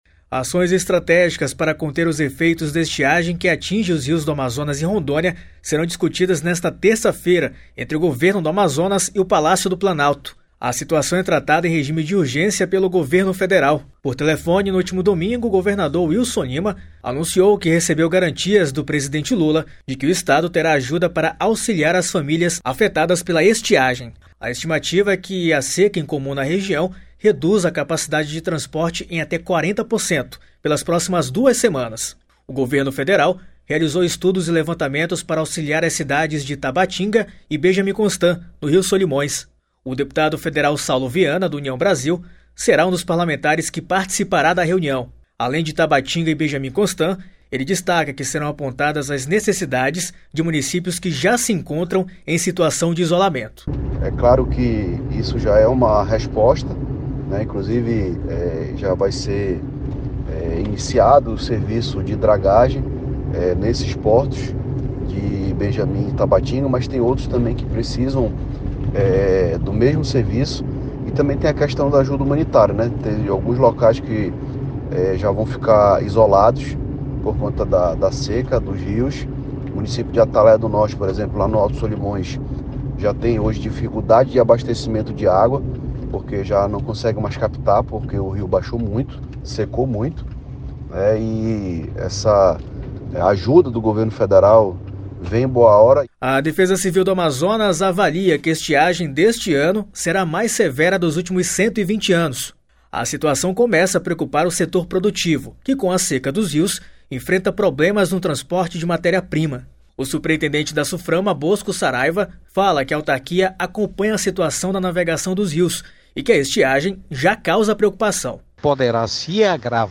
Reportagem
O deputado federal Saullo Vianna (União Brasil), será um dos parlamentares que participará da reunião.
Além de Tabatinga e Benjamin Constant, ele destaca que serão apontadas as necessidades de municípios que já se encontram em situação de isolamento. (Ouça)
O superintendente da Suframa, Bosco Saraiva, fala que a autarquia acompanha a situação da navegação dos rios e que a estiagem já causa preocupação. (Ouça)